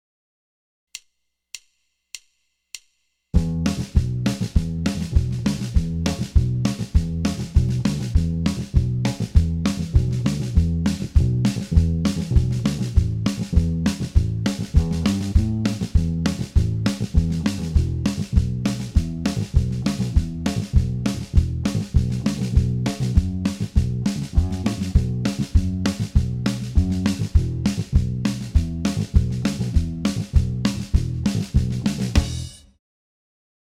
full speed version here.